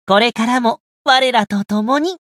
觉醒语音 今后也与吾等一起 これからも、我らと共に 媒体文件:missionchara_voice_626.mp3